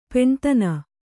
♪ peṇtana